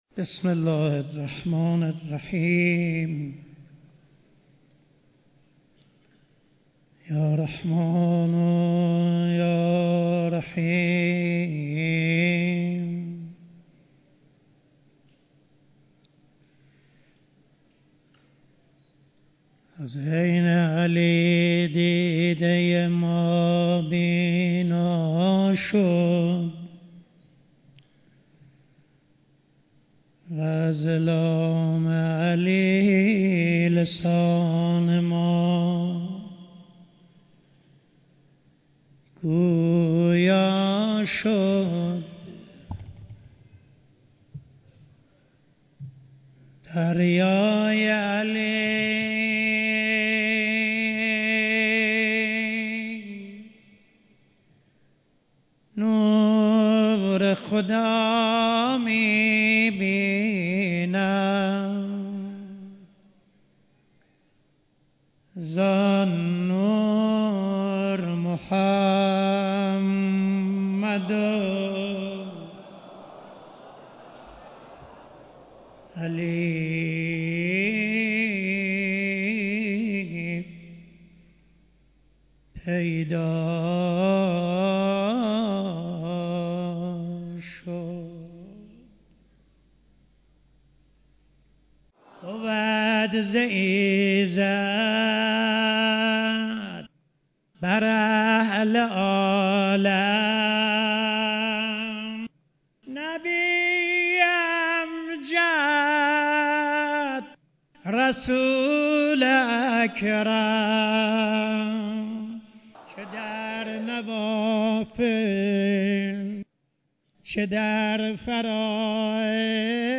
مراسم سوگواری شهادت مولای متقیان امام علی علیه السلام در حضور رهبر معظم انقلاب